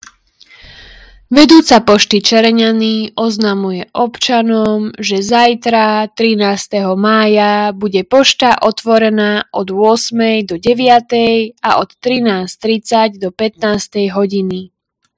Hlásenie obecného rozhlasu – Pošta Čereňany – zmena otváracích hodín 13.05.2025